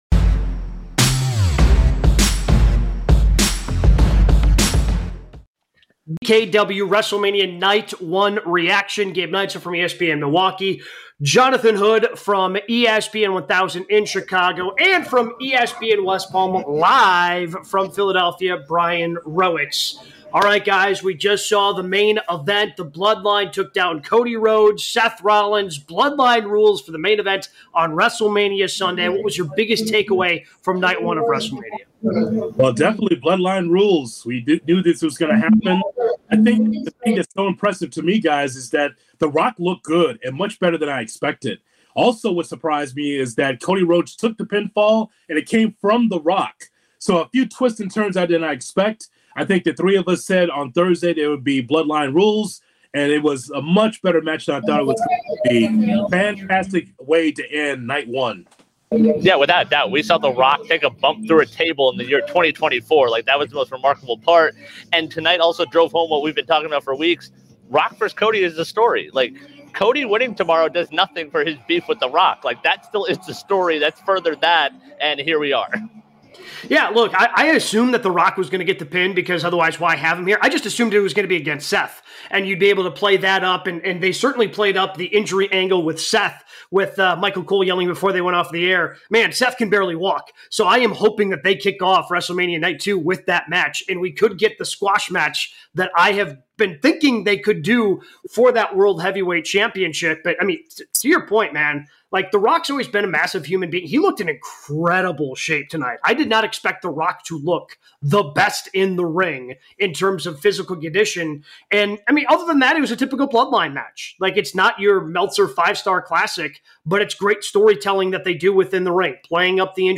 They react live to night 1 of WrestleMania 40.